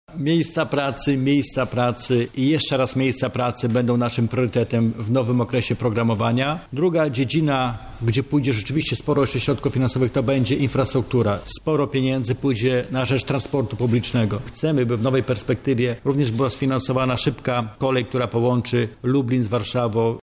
„ Na te lata sprecyzowaliśmy nasze priorytety.” – stwierdza Krzysztof Grabczuk, wicemarszałek województwa lubelskiego.